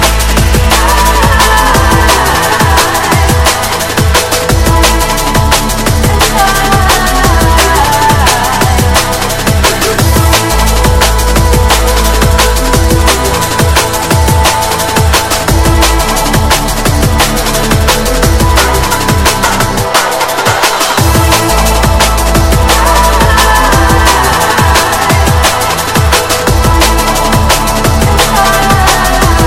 TOP >Vinyl >Drum & Bass / Jungle
TOP > HARD / TECH